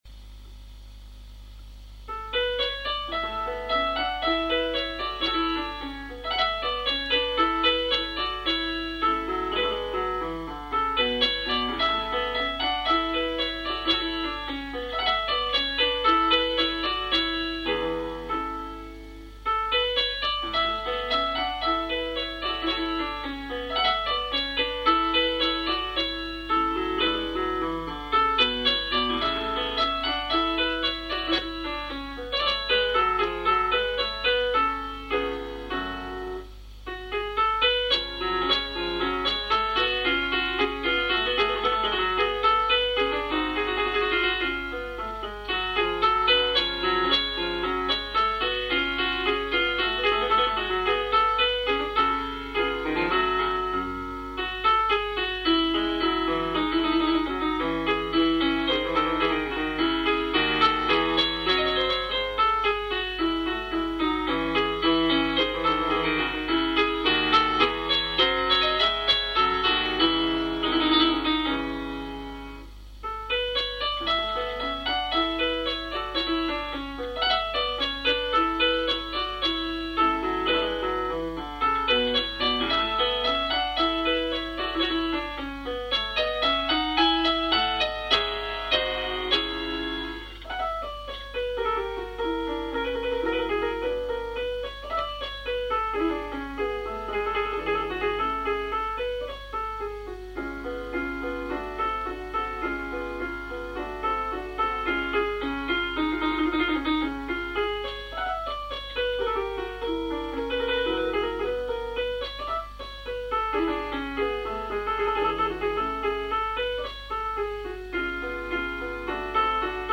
Gavotta per pianoforte